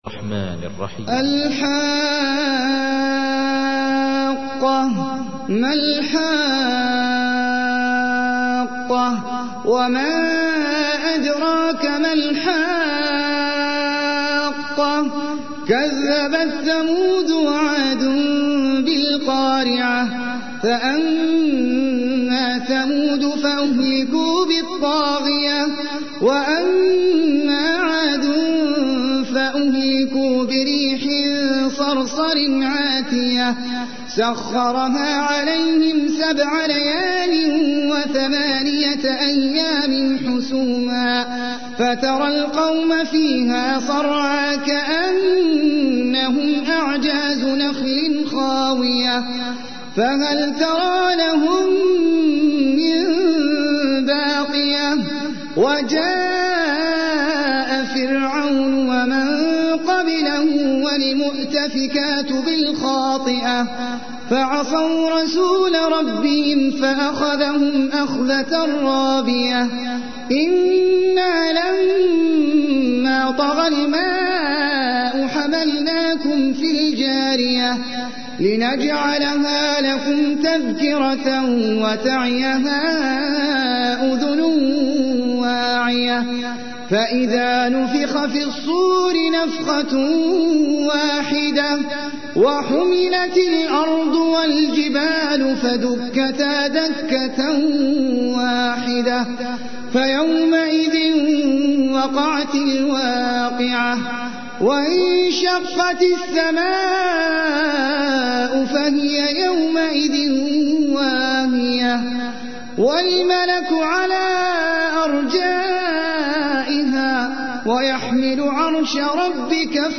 تحميل : 69. سورة الحاقة / القارئ احمد العجمي / القرآن الكريم / موقع يا حسين